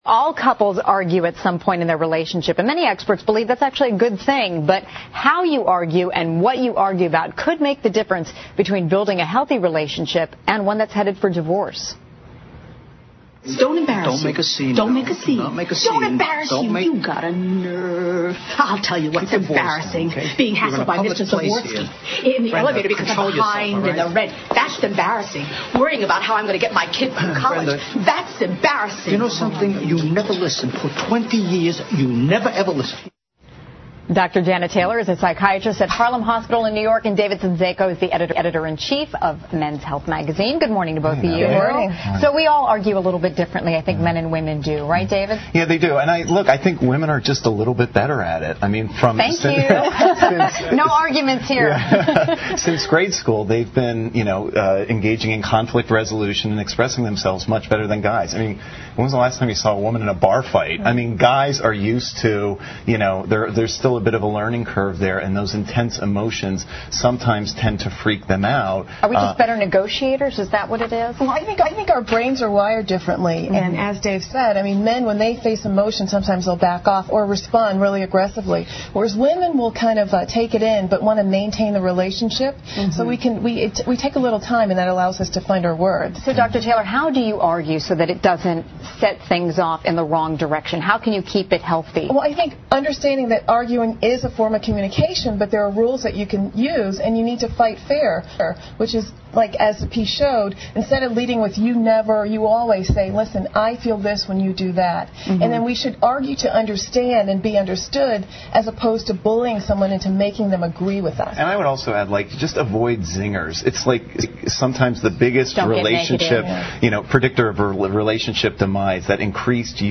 访谈录 Interview 2007-08-25&08-27, 情侣吵架，有助感情？